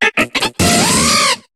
Cri d'Iguolta dans Pokémon HOME.